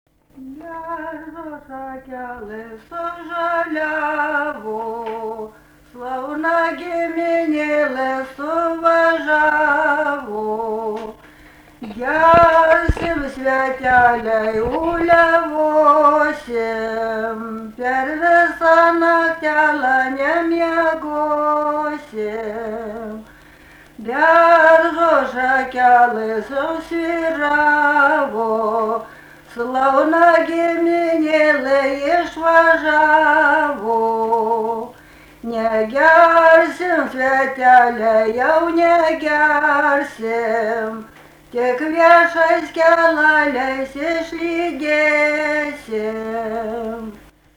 daina
Rudnia
vokalinis